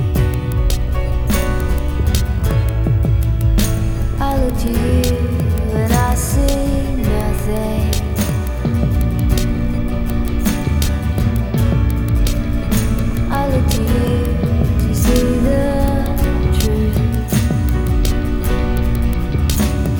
例では、2つのモノラル信号を配合を少し変えて混合した音源を2個、左右の音に録音したWavファイル を入力とします。
2つの信号を混合した音（右クリックでファイルをダウンロードして再生してください）
mixed.wav